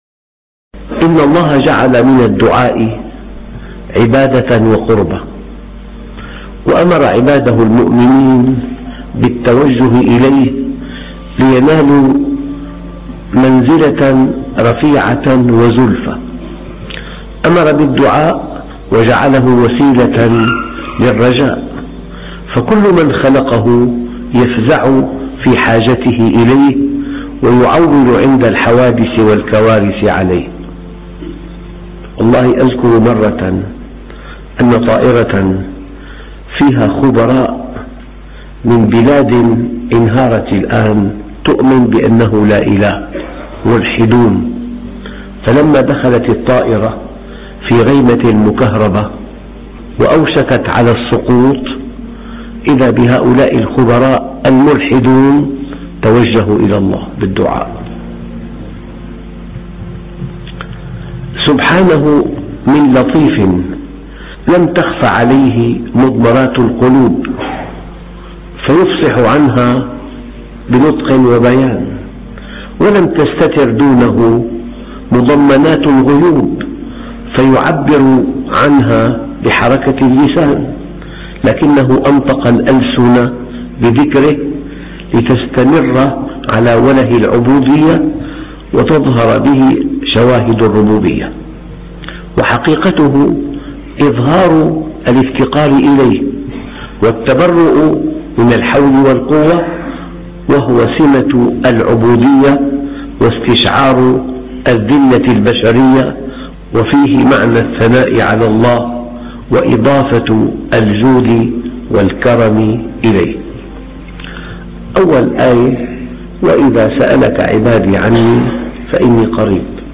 تعلم كيف تناجي ربك وسترى العجب ! موعظة مؤثرة للشيخ